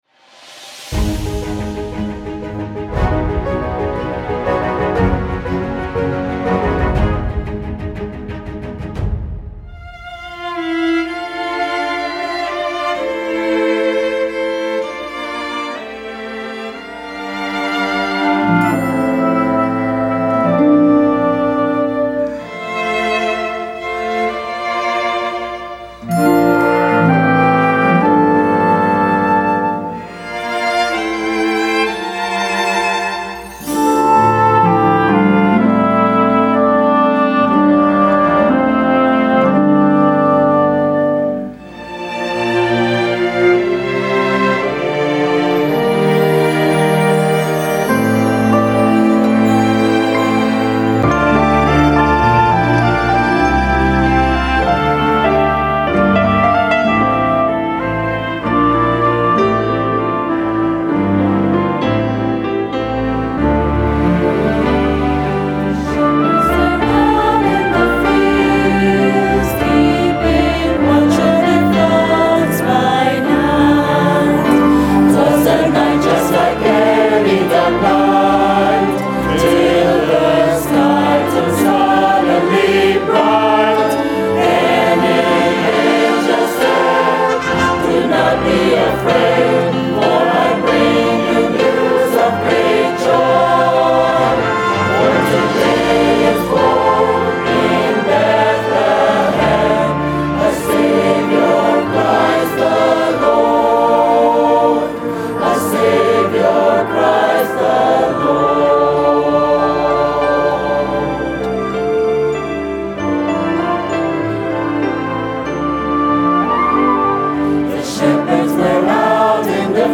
A message from the series "What Matters Most."